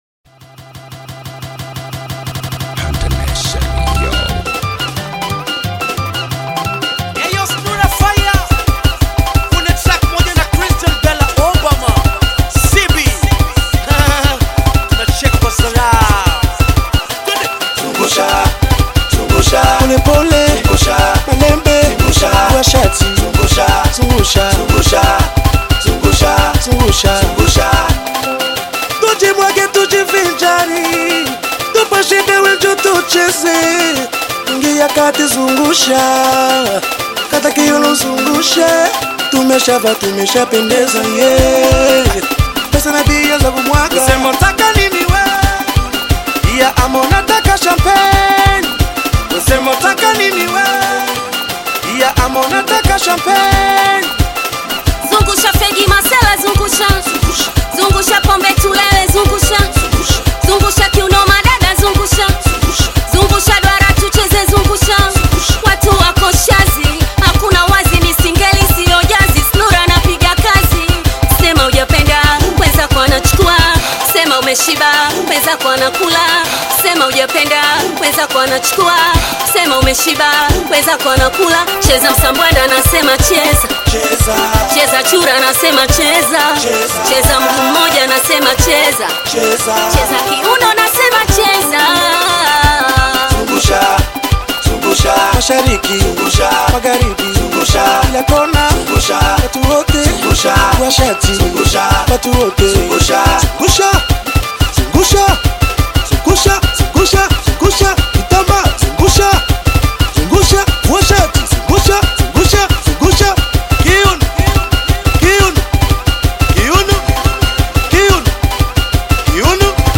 catchy Afrobeats single
the track’s energetic rhythm and collaborative chemistry